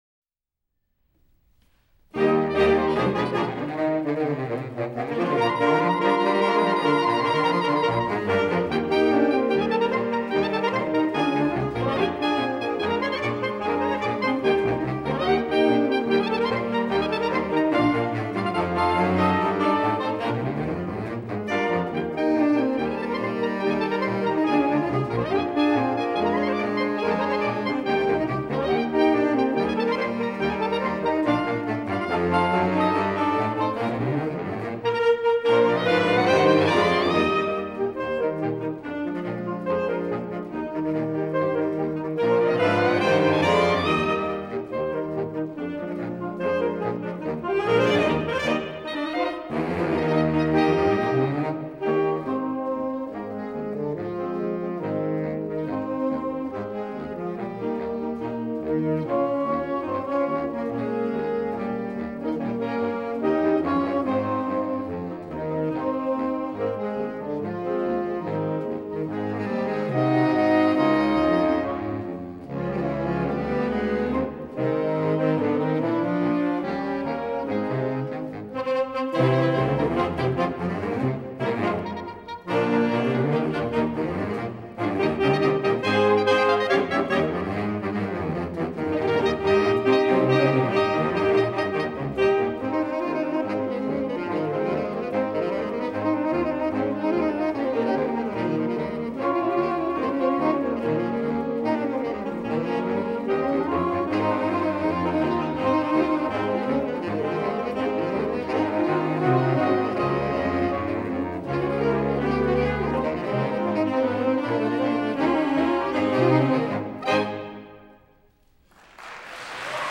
Voicing: Saxophone Choir